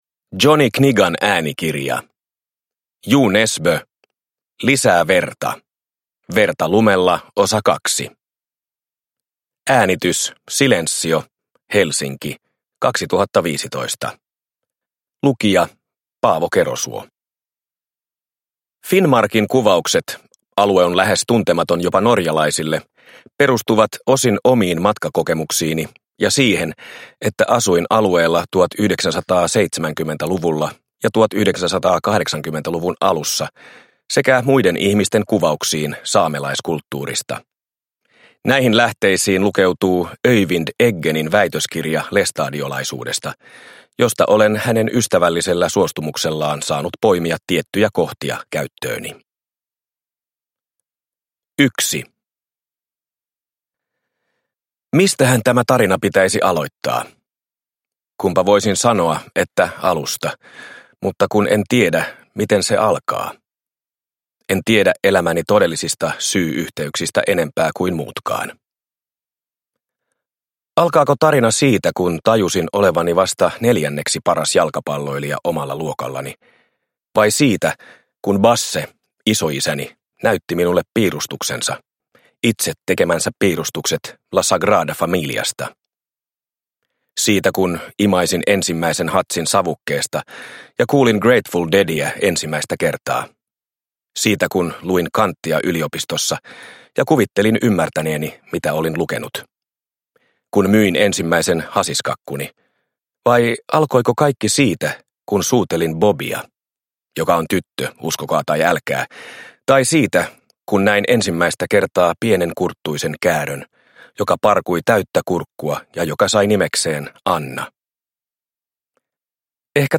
Verta lumella II - Lisää verta – Ljudbok – Laddas ner